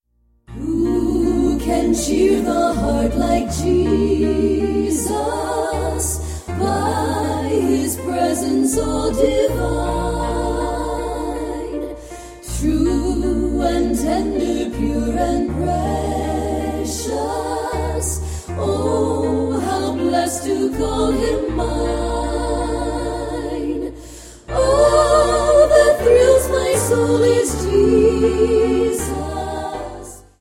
mit leichter instrumentaler begleitung
• Sachgebiet: Praise & Worship